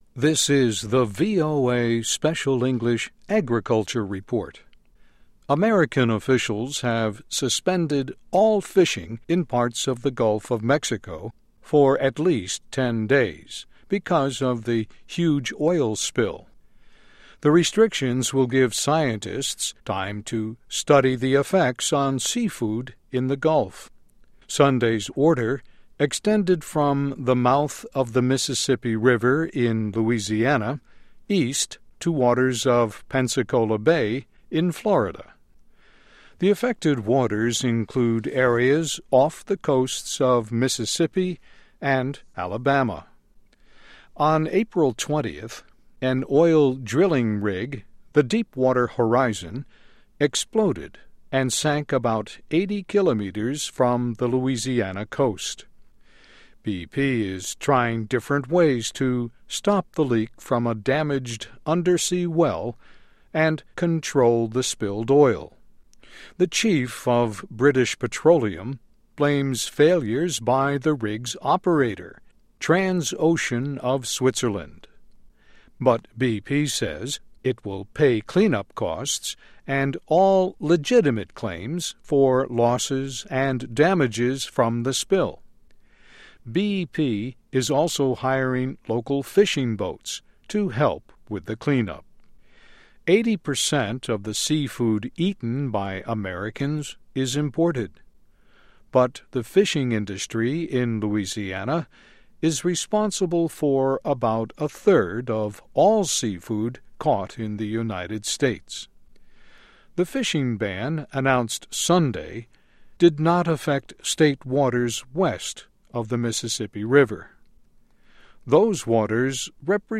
VOA Special English - Text & MP3